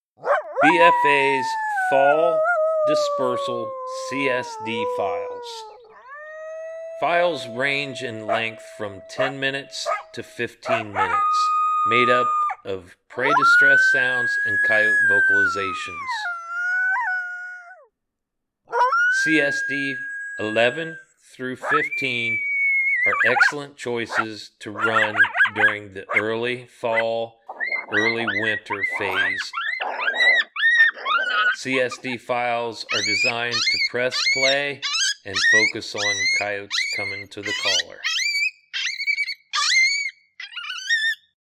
V = Vocals
Each BFA Spring CSD File is made up of our most popular Coyote Howls, Coyote Social Vocalizations, Coyote fights and Prey Distress Files.